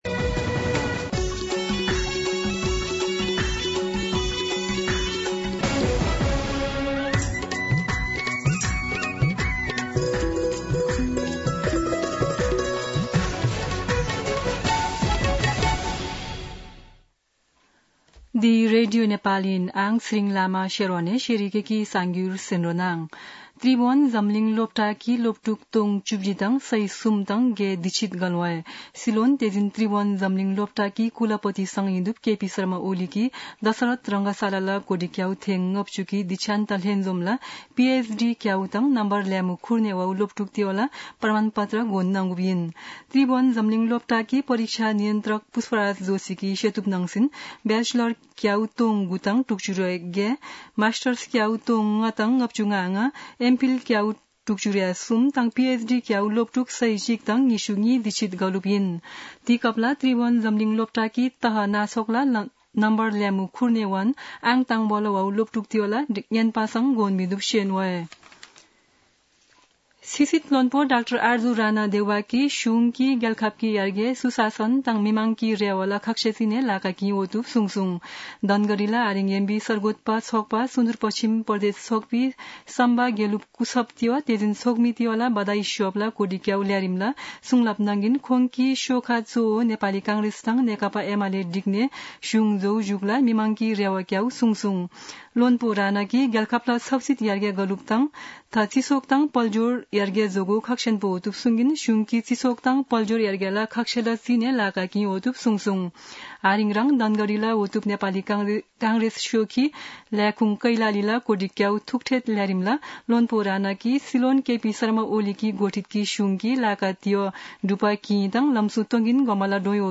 शेर्पा भाषाको समाचार : १४ पुष , २०८१